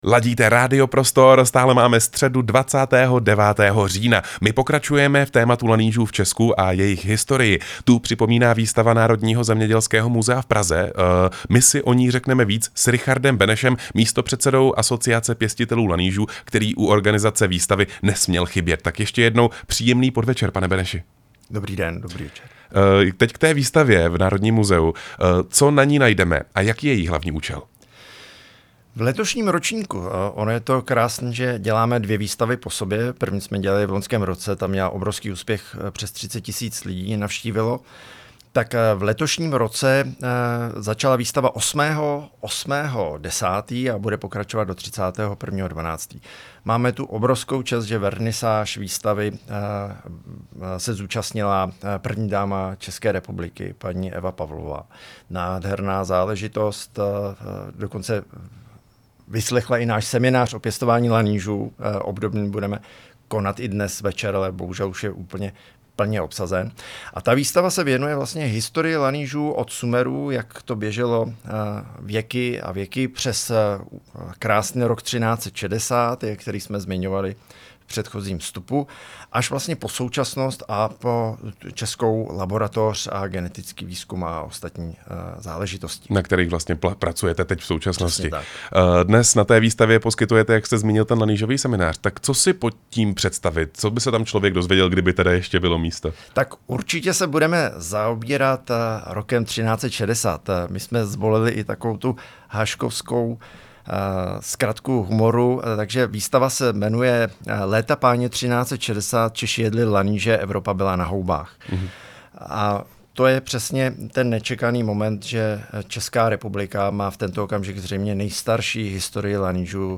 rozhovor rádia prostor